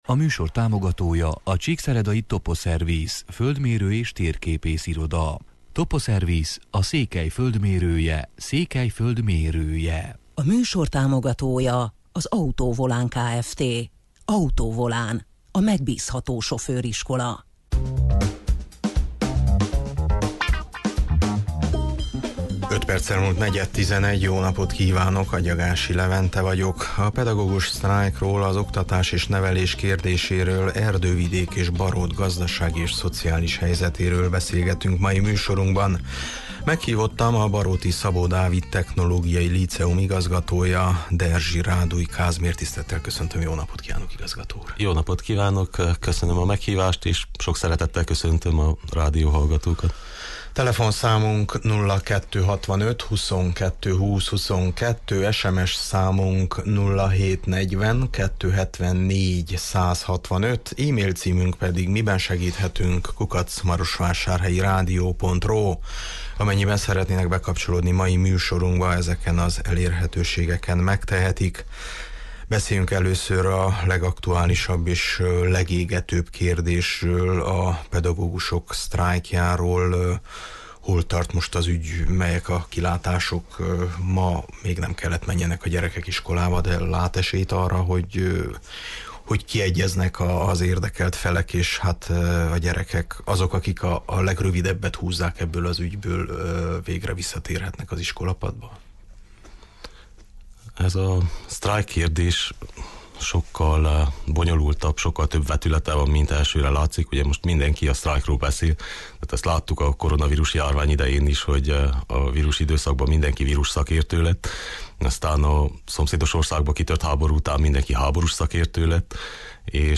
A baróti közélet alakulásáról, a pedagógusok sztrájkjáról, az oktatás és nevelés kérdéséről, Erdővidék és Barót gazdasági és szociális helyzetéről beszélgetünk mai műsorunkban.